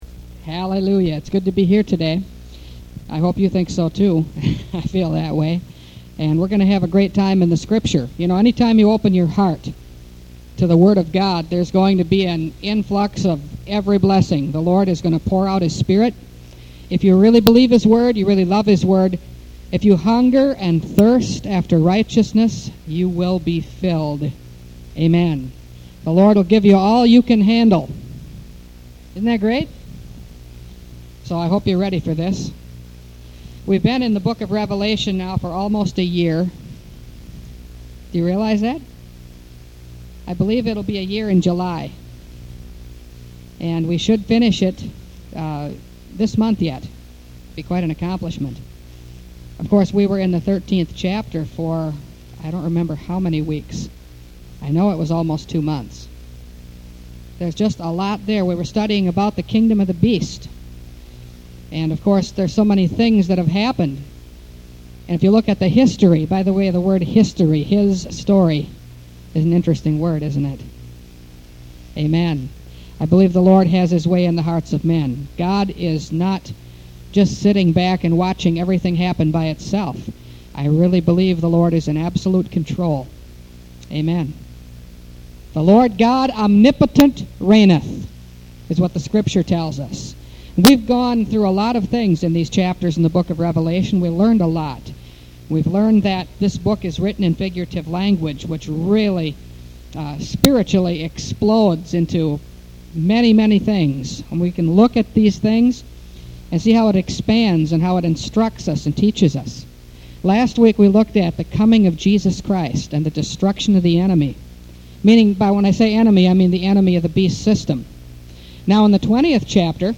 Revelation Series – Part 42 – Last Trumpet Ministries – Truth Tabernacle – Sermon Library